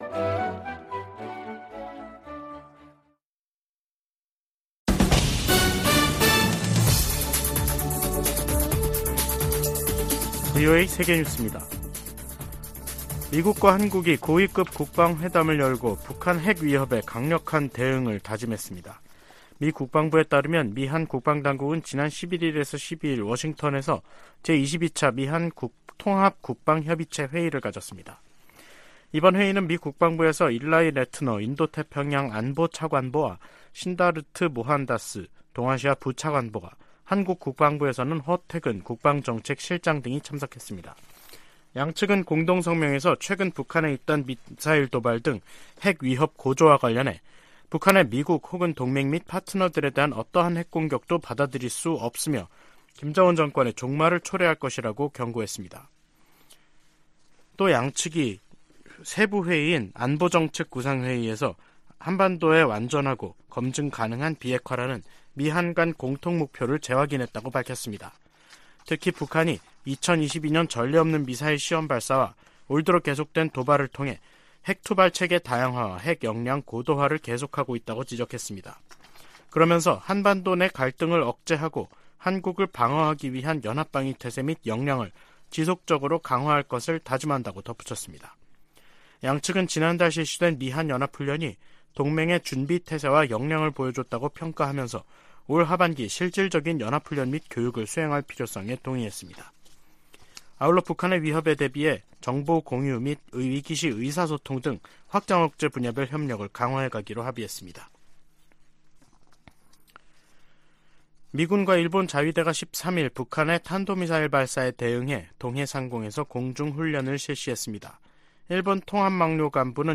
VOA 한국어 간판 뉴스 프로그램 '뉴스 투데이', 2023년 4월 13일 3부 방송입니다. 북한이 중거리급 이상의 탄도 미사일을 동해쪽으로 발사했습니다.